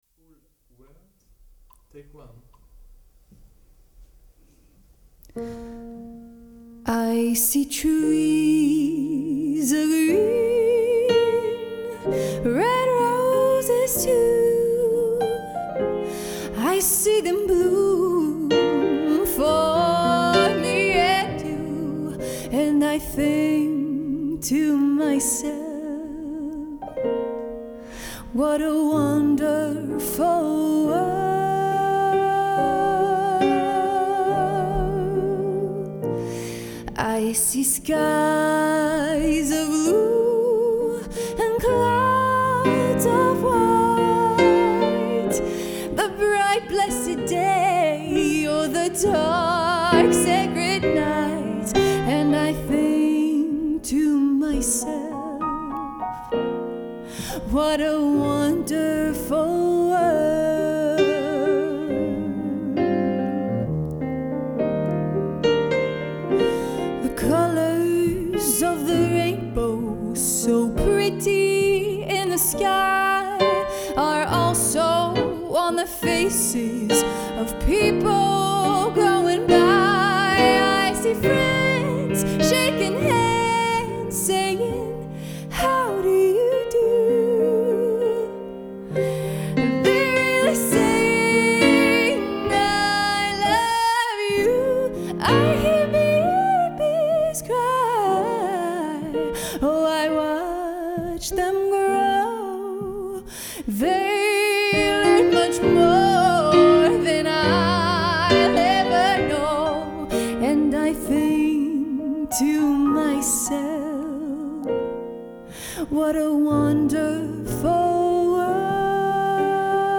Piano + Voix